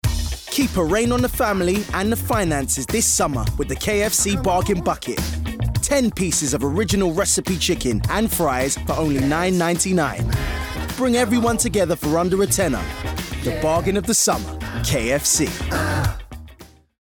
20/30's London, Fresh/Funny/Charismatic
Commercial Showreel Argos